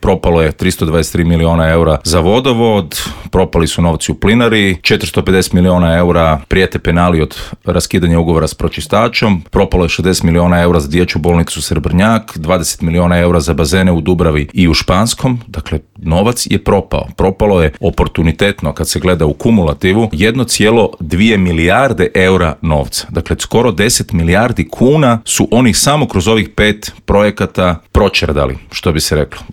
Zagreb već zna nekoliko kandidata za gradonačelnika, a svoju kandidaturu objavio je i nezavisni kandidat Davor Bernardić koji je u Intervjuu Media servisa poručio: "Imamo rješenje za prometne probleme, a Tomaševićevi najavljeni projekti su samo prazno predizborno obećanje. Nema šanse da Maksimir bude gotov do 2028. kao ni Centar za gospodarenje otpadom."